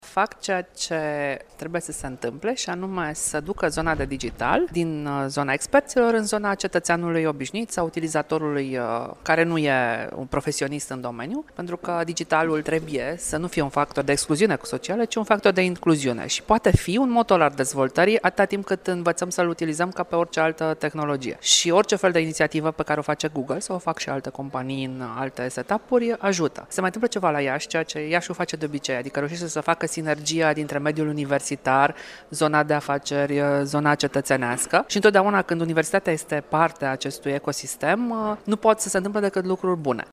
Reprezentantul Ministerului Comunicaţiilor, secretarul de stat Maria Catrina, a declarat că în cele 18 luni de când funcţionează, proiectul de la Iaşi a reuşit să fie un motor al dezvoltării IMM-urilor din zonă: